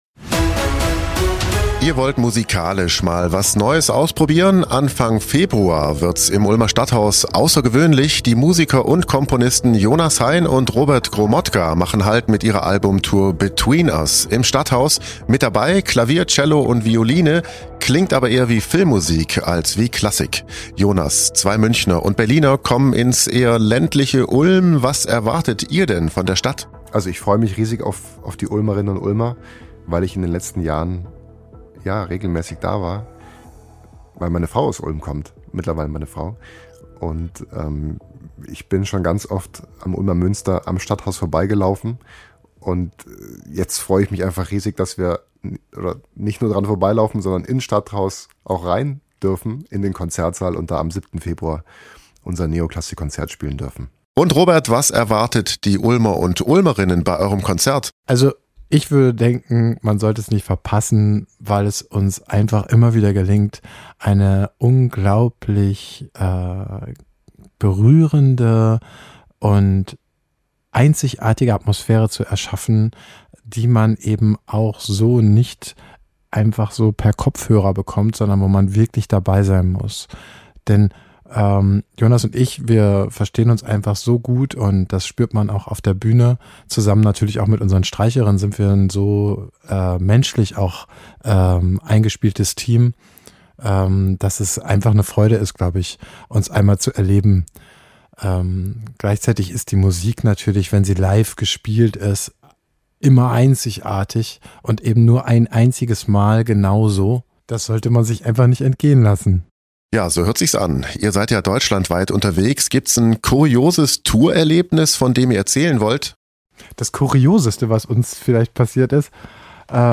in diesem Interview.